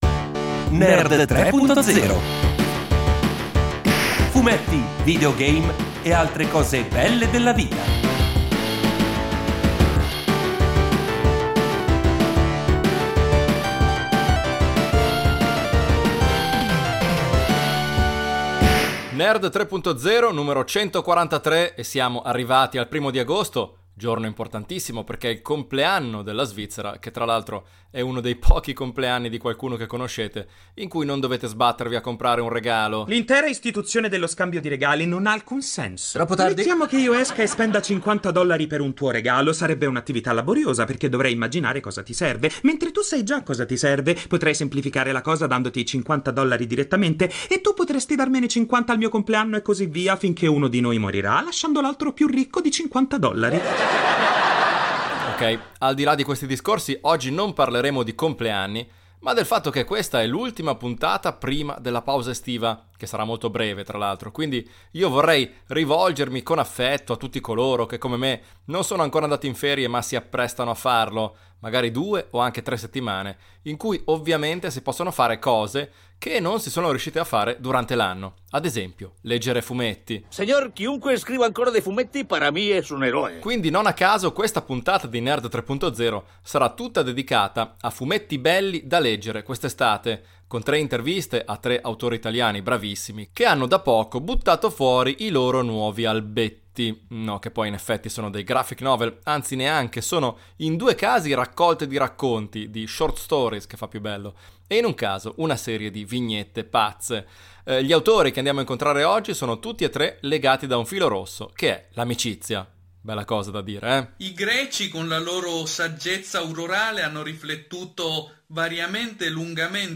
Tre interviste esclusive a tre geni del fumetto italiano